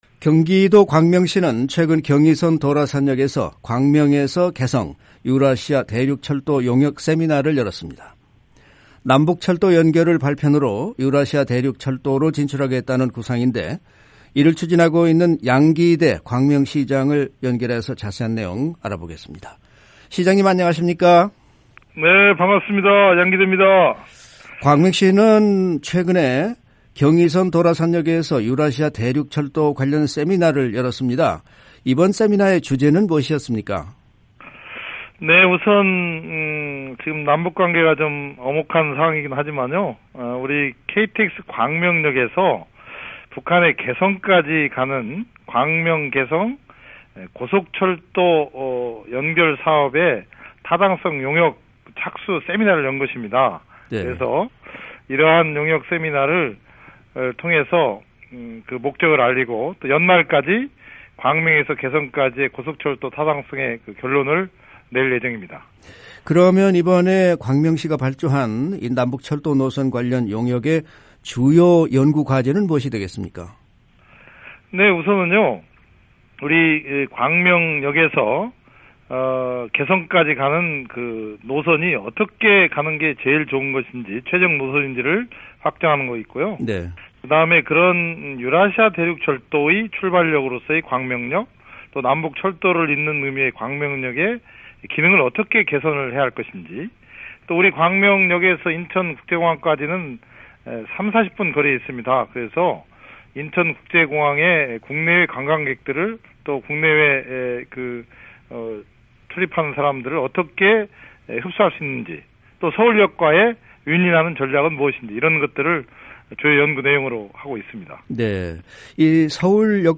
한국 경기도 광명시가 최근 경의선 도라산역에서 ‘광명에서 개성, 유라시아 대륙철도 용역 세미나’를 열었습니다. 남북철도 연결을 발판으로 유라시아 대륙철도로 진출하겠다는 구상인데요, 이를 추진하고 있는 양기대 광명시장을 인터뷰 했습니다.